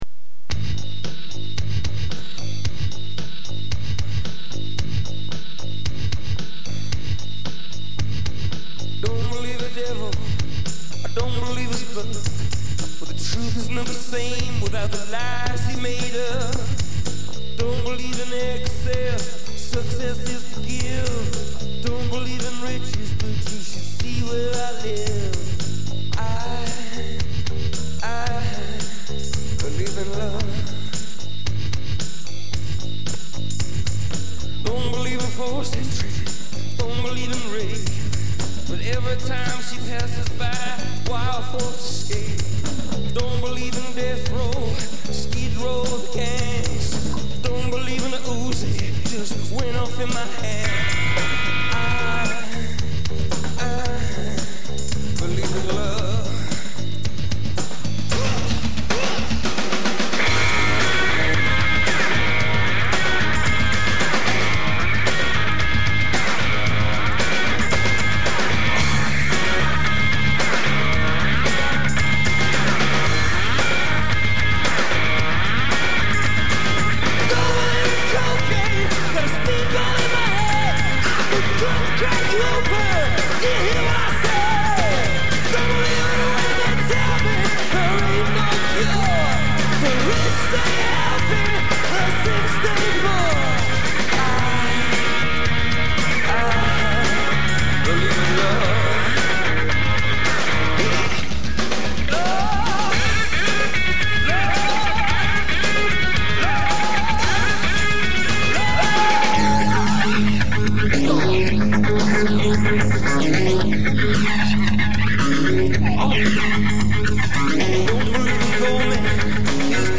가사도 맘에 들지만, 빠르고 강한 비트의 기타연주도 일품입니다.